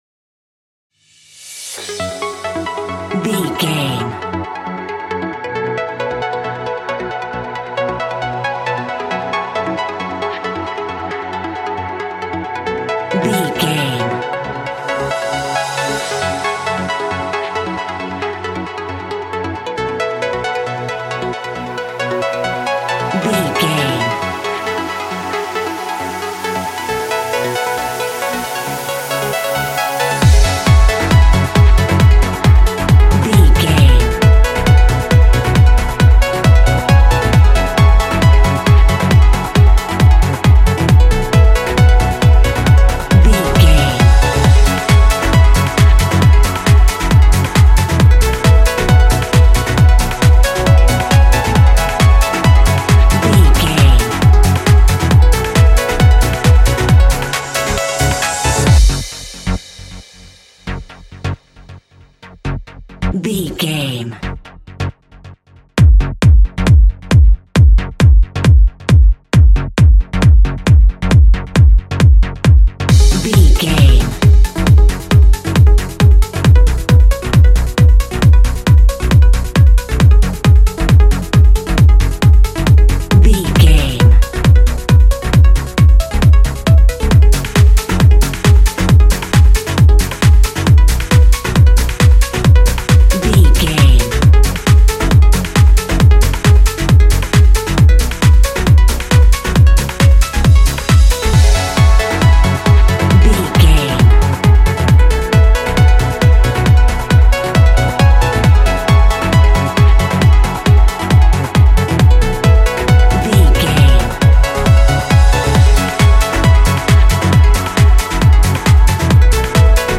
Ionian/Major
Fast
futuristic
frantic
driving
energetic
hypnotic
industrial
powerful
drum machine
synthesiser
acid house
electronic
uptempo
synth leads
synth bass